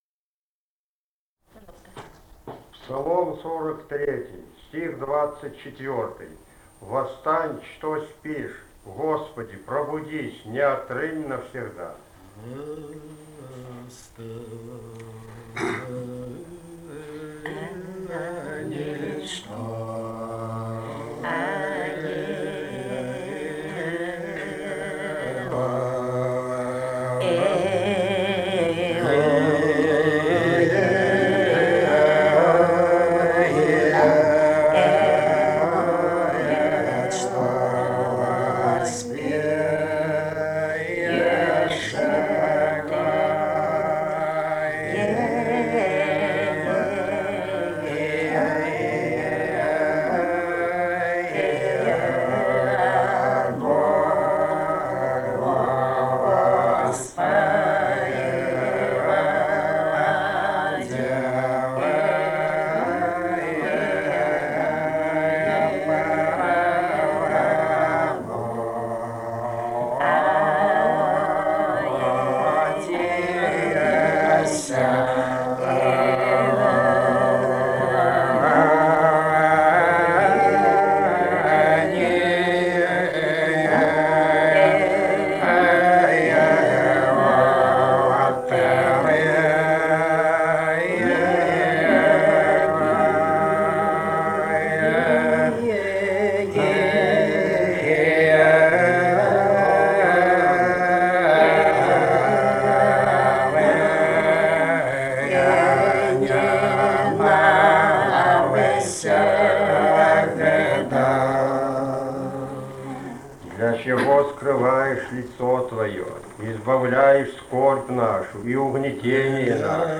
полевые материалы
Грузия, г. Тбилиси, 1971 г.